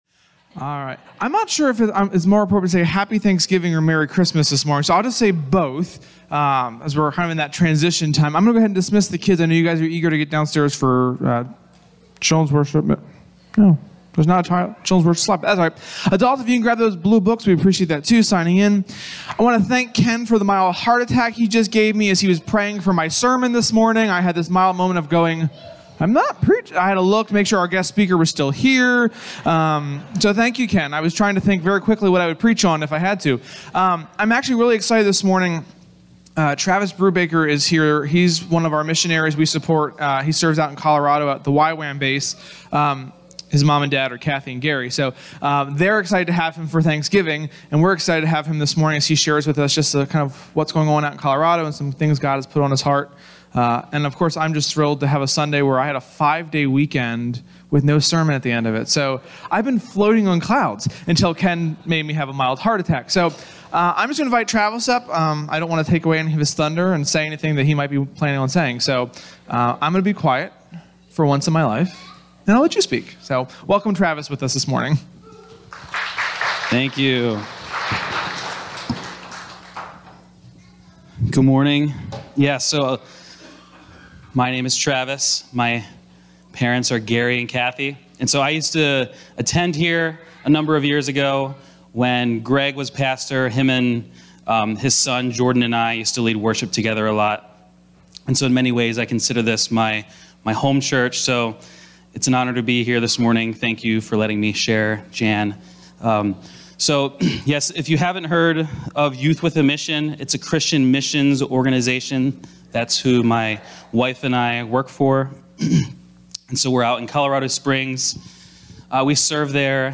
Related guest speaker , YWAM